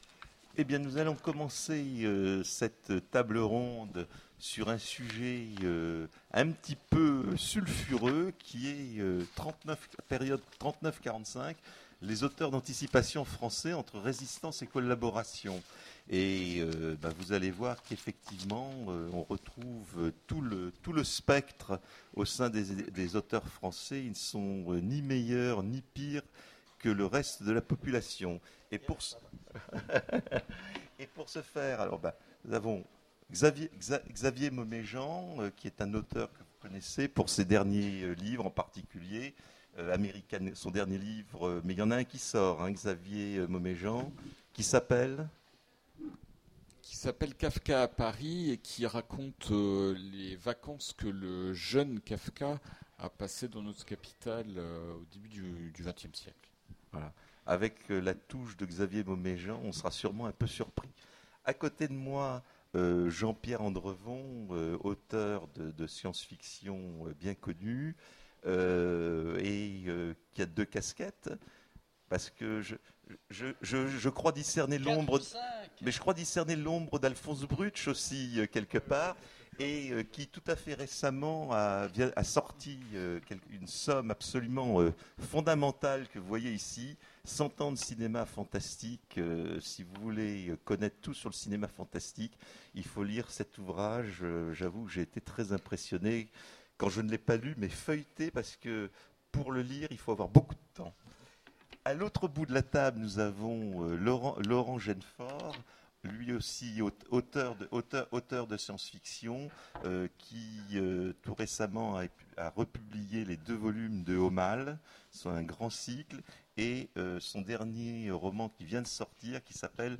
Imaginales 2015 : Conférence 39-45, les auteurs d'anticipation français...